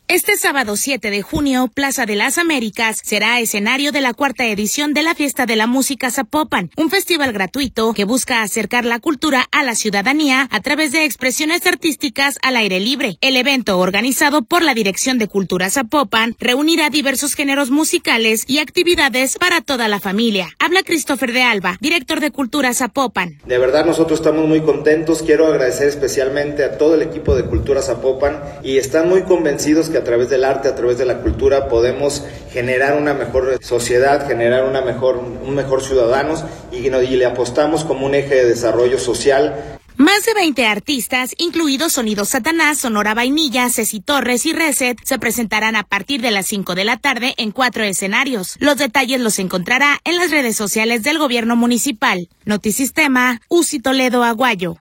Habla Christopher de Alba, director de Cultura de Zapopan: